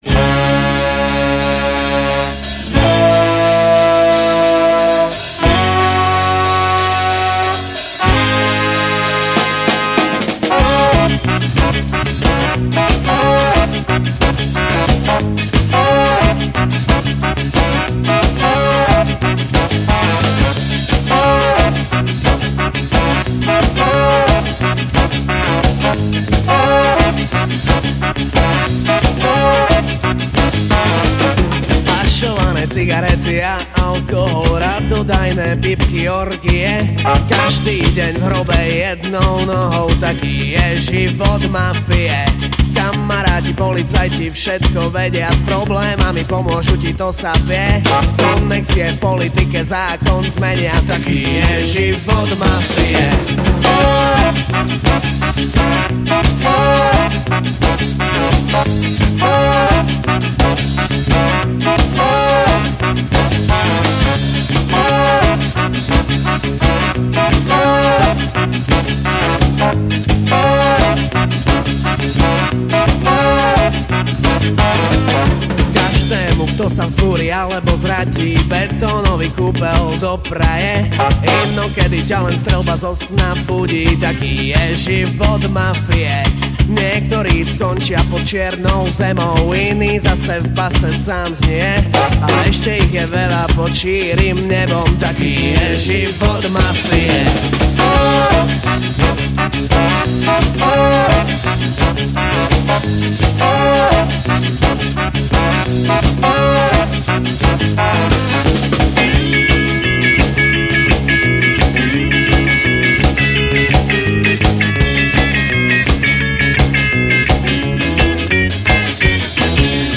- povodna radio verzia